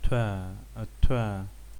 labzd voiceless alveolar stop
[tʷ] Archi, Abkhaz, Lao, Paha, Ubykh
Labialized_voiceless_alveolar_plosive.ogg.mp3